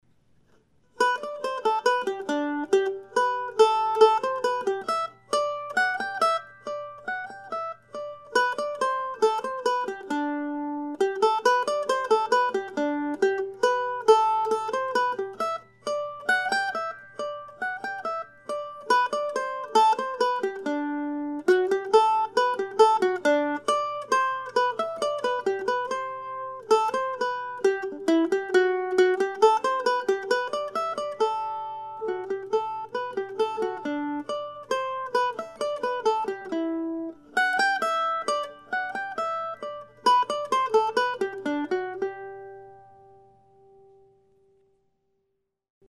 As stated in my previous post, these are short pieces modeled after James Oswald's 18th century divertimentos for "guittar" and titled after some of my favorite places here in the Decorah area.
I'll be playing all ten of these Postcards tomorrow night at Java John's Coffee House, along with music by James Oswald and others, from 7:00-9:00 p.m. Drop by if you would like to hear some solo mandolin music.